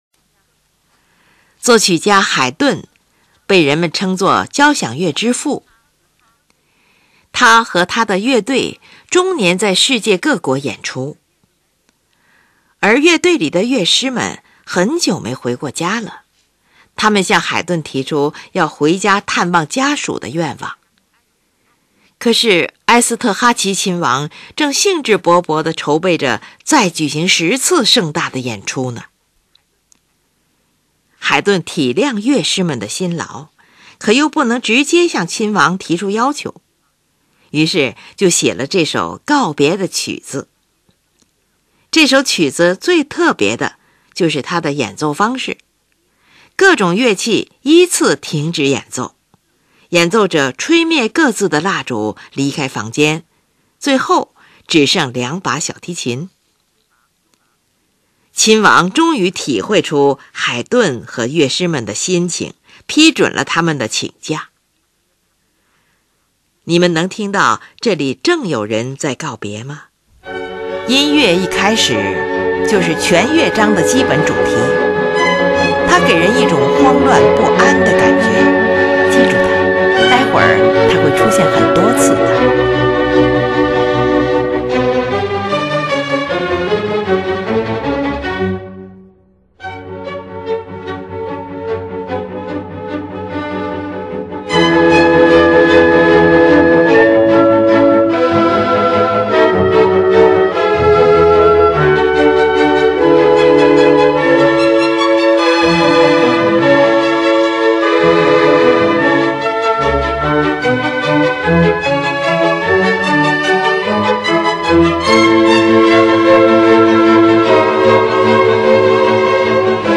第一乐章：音乐一开始就是全乐章的基本主题，它给人一种慌乱不安的感觉。
第一乐章，奏鸣曲式。
这个主题的呈示汇成了不可遏制的急流，使音乐的进行越来越激烈。